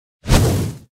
Minecraft Fireball Efeito Sonoro: Soundboard Botão
Minecraft Fireball Botão de Som